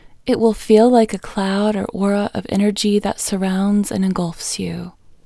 LOCATE Short OUT English Female 11